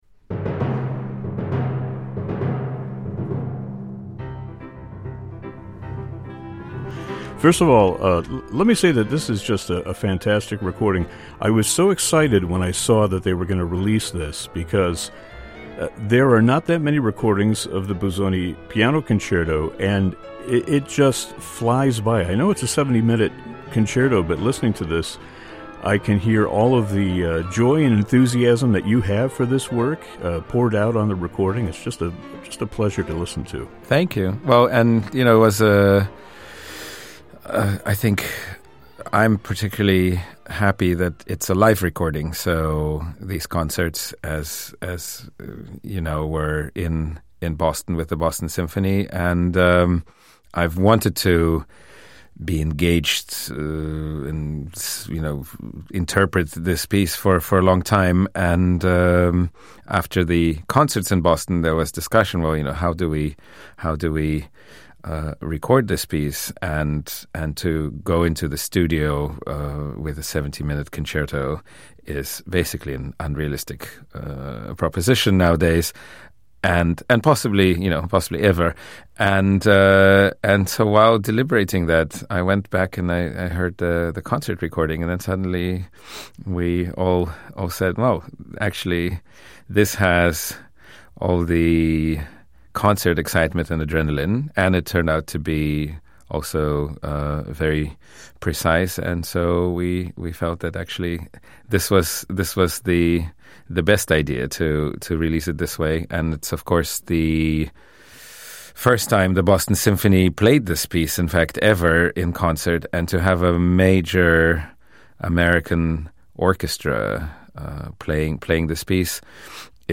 With his latest album, powerhouse pianist Kirill Gerstein adds to the collection his own formidable interpretation, which has been years in the making. Kirill joins us for a conversation about the work, and his long-time fascination with Busoni's music.